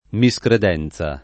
[ mi S kred $ n Z a ]